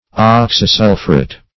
Oxysulphuret \Ox`y*sul"phu*ret\, n.
oxysulphuret.mp3